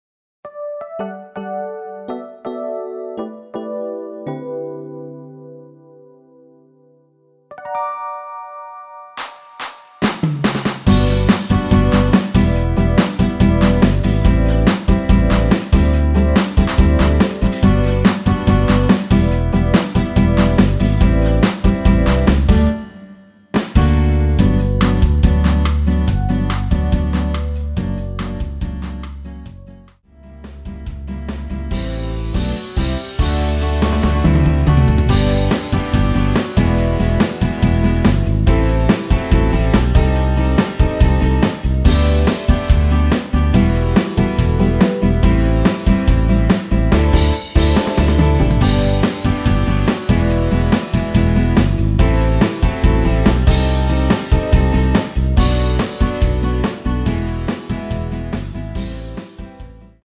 엔딩이 페이드 아웃이라 8마디로 엔딩을 만들었습니다.
앞부분30초, 뒷부분30초씩 편집해서 올려 드리고 있습니다.